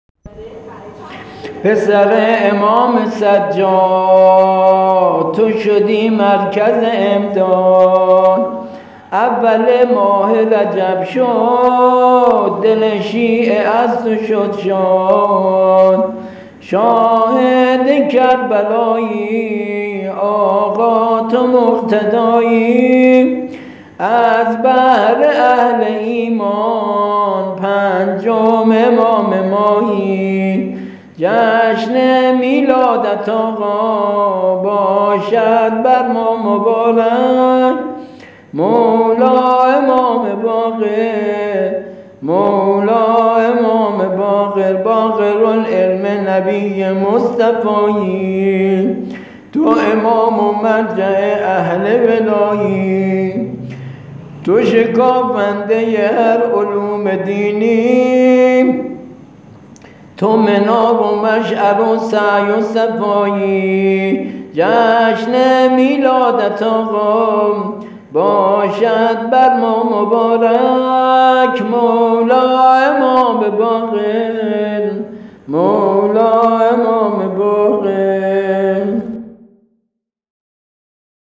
سرود میلاد امام باقر(ع)
سبک افتخاری